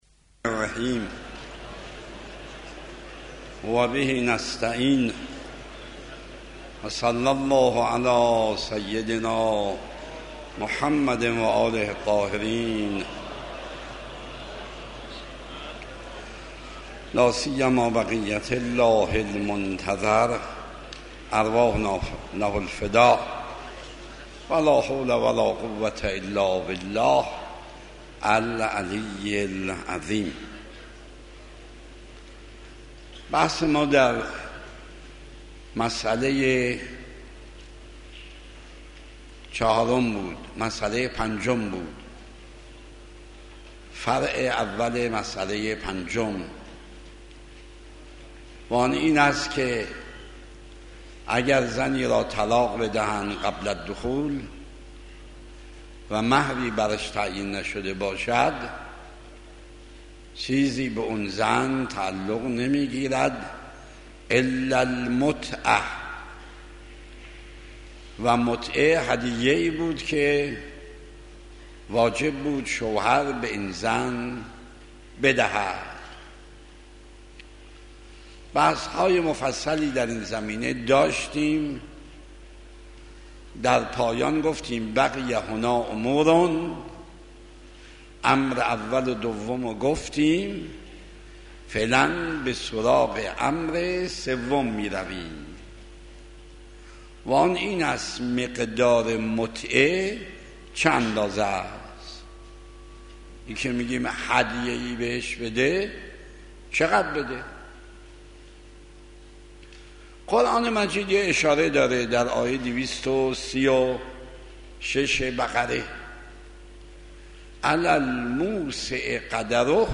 آيت الله مکارم شيرازي - نکاح | مرجع دانلود دروس صوتی حوزه علمیه دفتر تبلیغات اسلامی قم- بیان